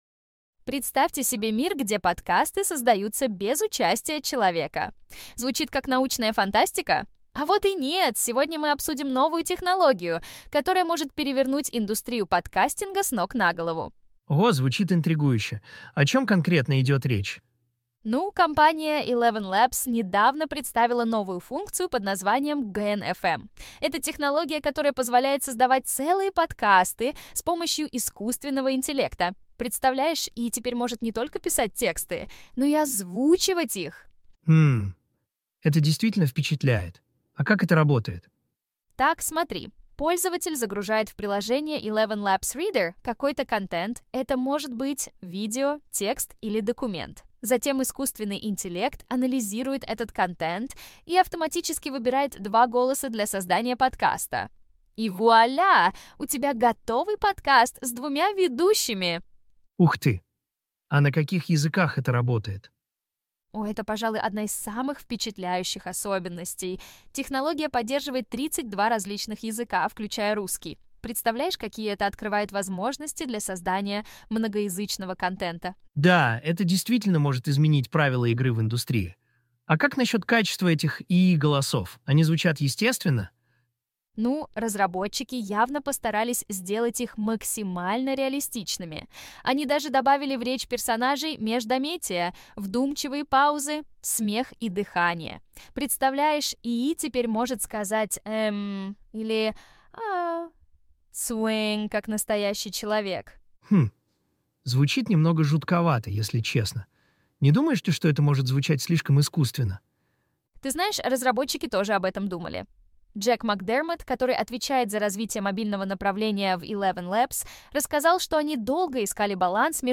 ElevenLabs выпустила инструмент для создания ИИ-подкастов с синтетическими ведущими
Голосовой ИИ-стартап ElevenLabs представил функцию GenFM, которая позволяет генерировать подкасты с двумя ведущими на базе разных типов контента.
Чтобы сделать подкаст, мы загрузили в программу текст этой новости.
Примечательно, что ИИ добавляет в речь персонажей междометия и «вдумчивые паузы», чтобы «очеловечить» запись.